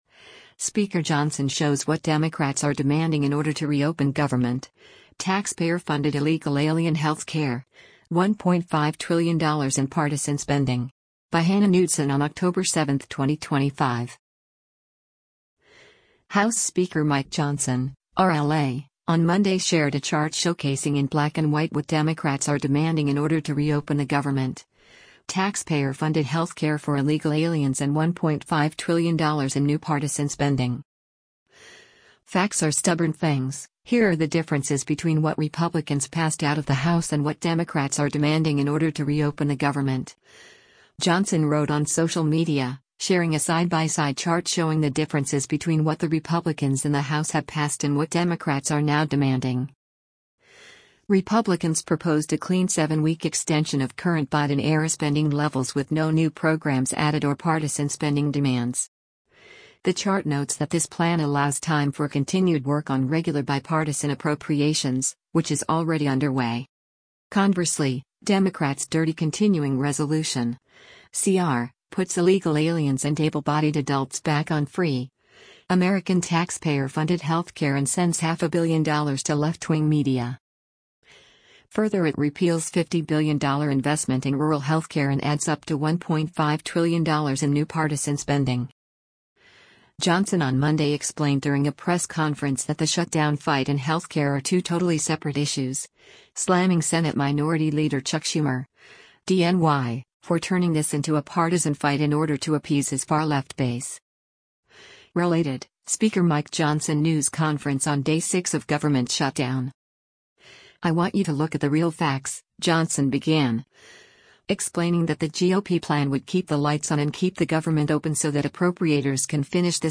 RELATED —  Speaker Mike Johnson News Conference on Day 6 of Gov’t Shutdown…